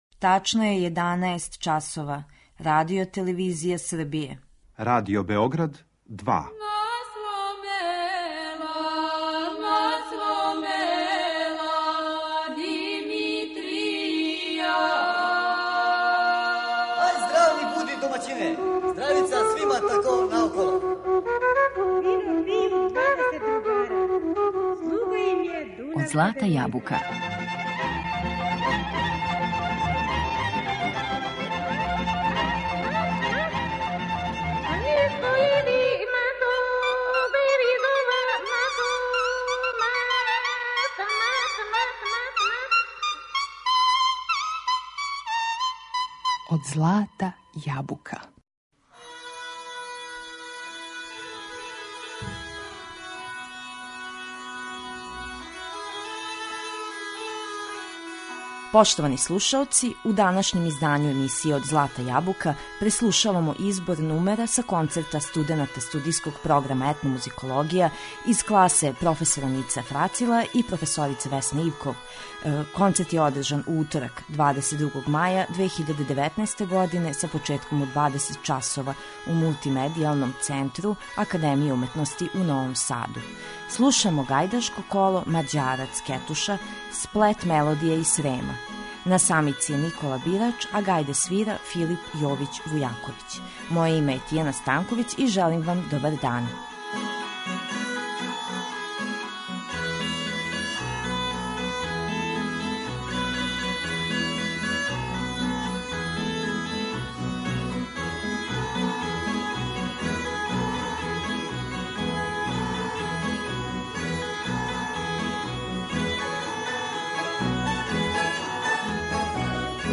Избор са концерта студената студијског програма Етномузикологија
Са концерта студената студијског програма Етномузикологија Академије уметности у Новом Саду.